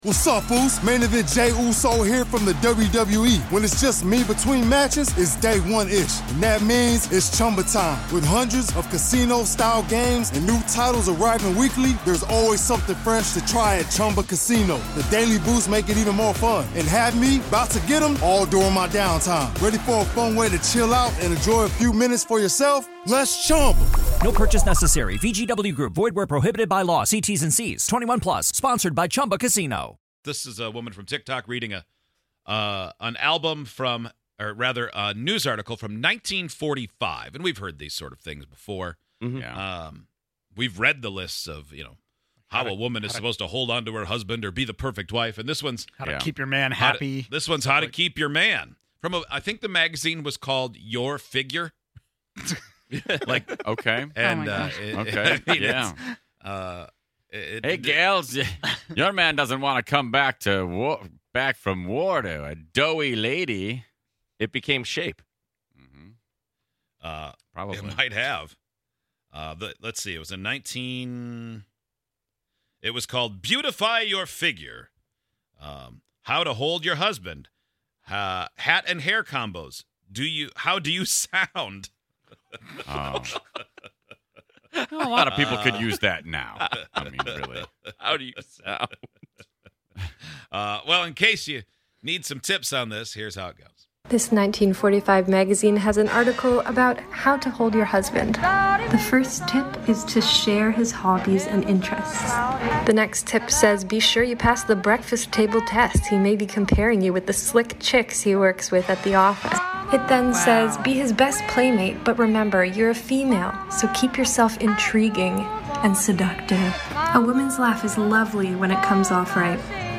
On today's show, we read a magazine article from 1945 that teaches women how to hold on to their husband.